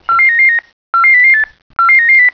CODES D'APPELS SELECTIFS - AS.
Exemple de 3 codes à 5 tons CCIR :
3x5tons.wav